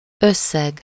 Ääntäminen
Synonyymit ascendant somme d'argent Ääntäminen France: IPA: [mɔ̃.tɑ̃] Haettu sana löytyi näillä lähdekielillä: ranska Käännös Ääninäyte 1. összeg Suku: m .